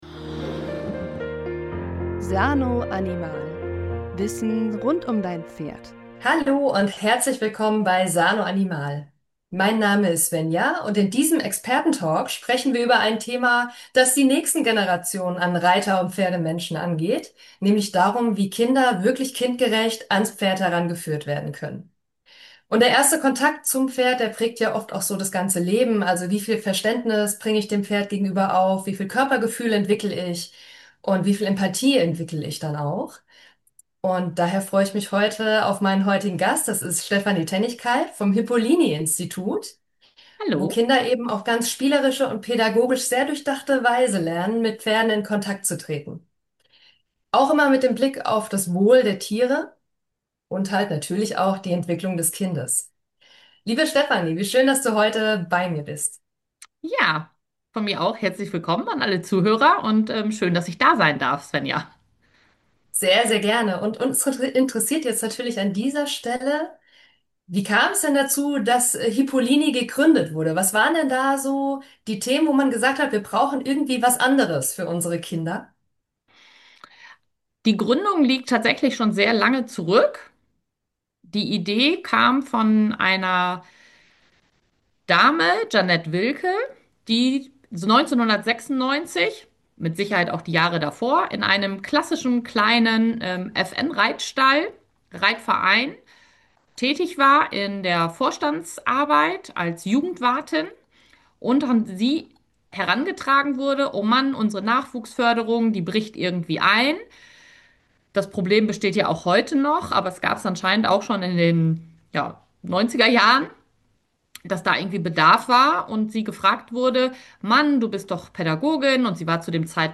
Expertentalk #10 – Herzenssache Reiteinstieg